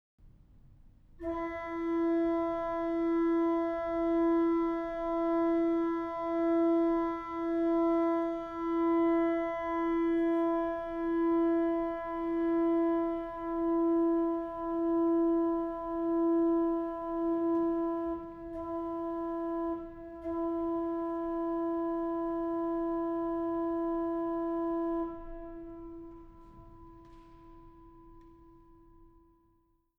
Alle Kompositionen erleben hier ihre Ersteinspielung.